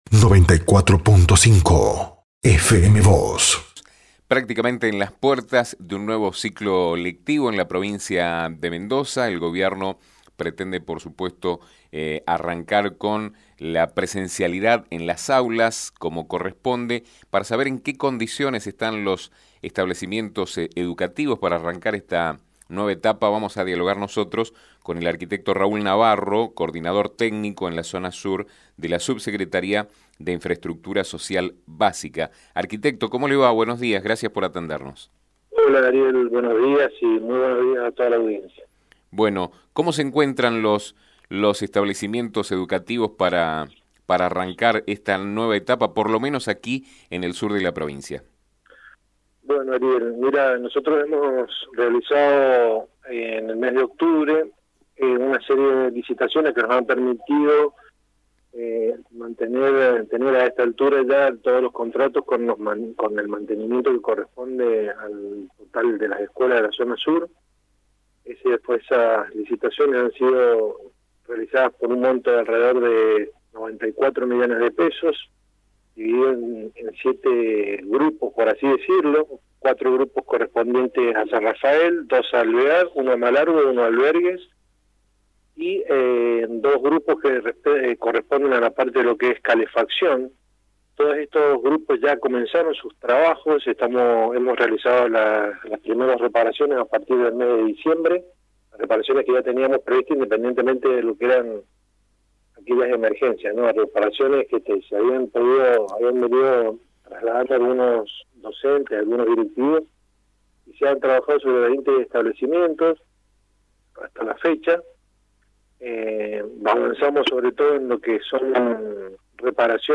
indicó en FM Vos (94.5) que se han realizado licitaciones por 94 millones de pesos.